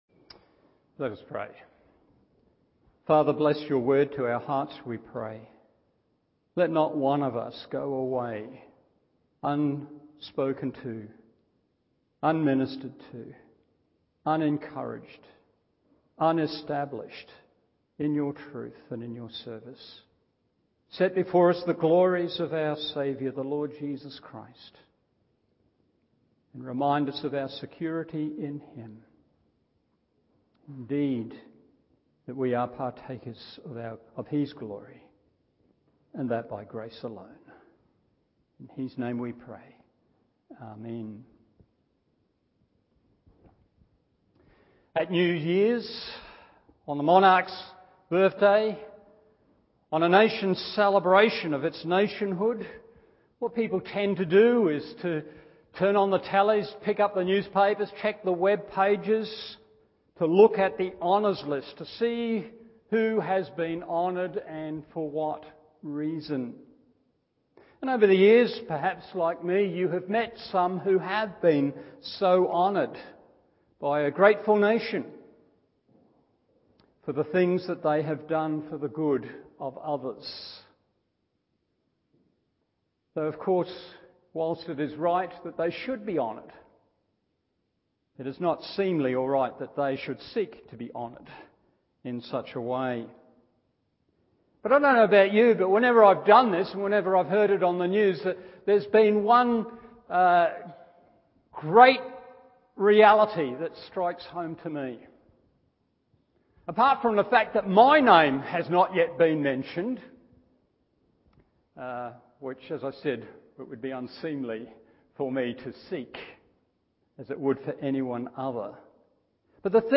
Evening Service Luke 7:17-30 1. How Jesus Honours 2. What Jesus Honours 3.